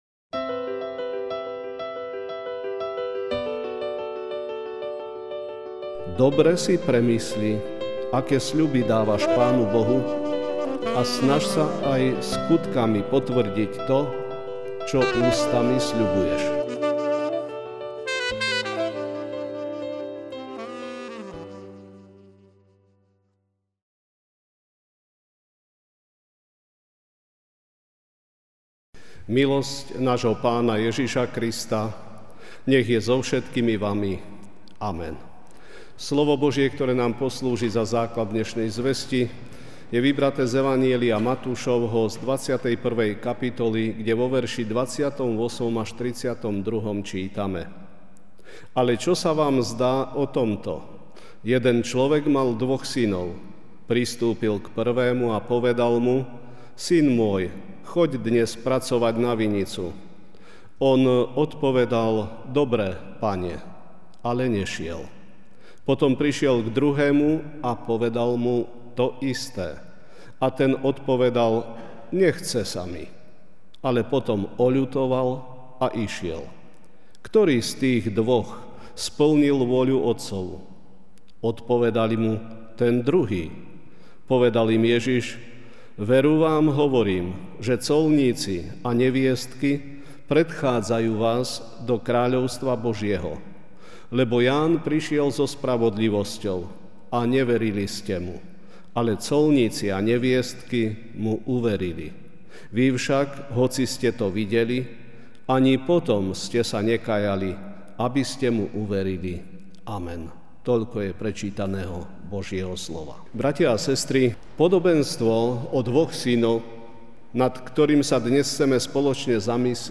kázeň-3.9.mp3